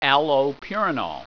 Pronunciation
(al oh PURE i nole)